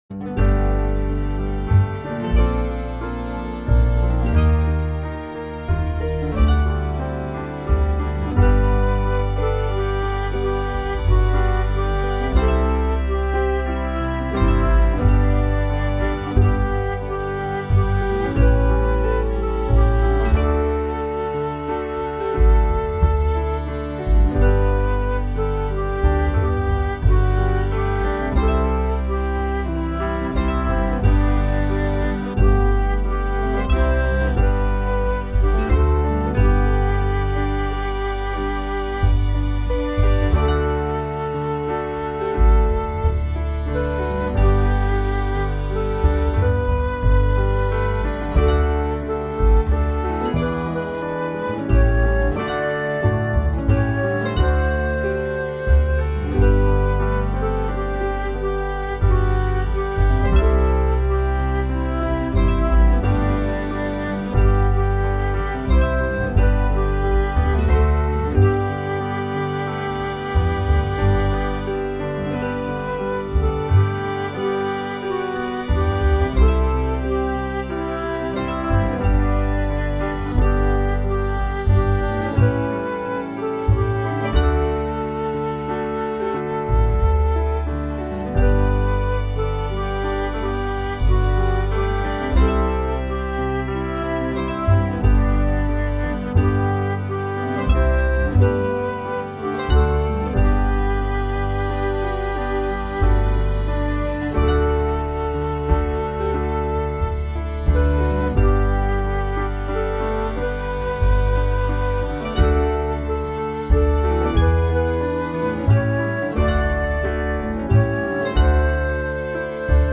There is a high risk of schmaltz.